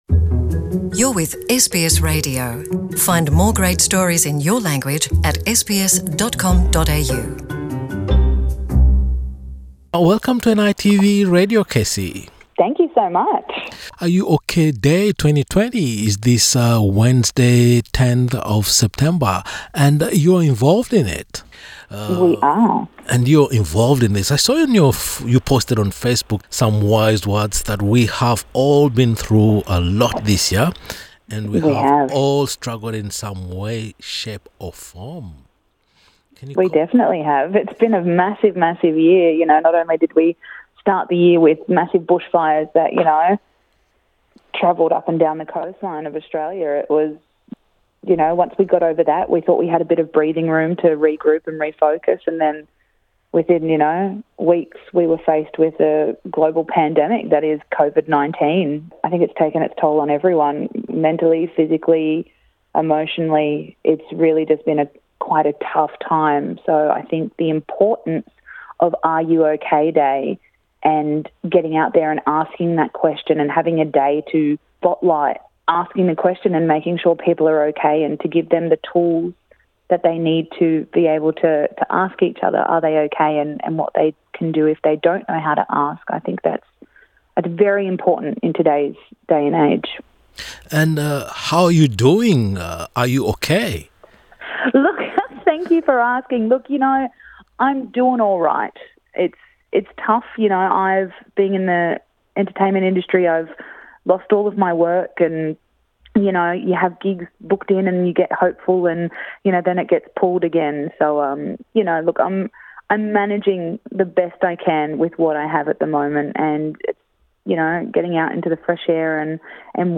In a conversation with NITV Radio, the singer songwriter highlighted the very difficult circumstances of R U OK?Day 2020.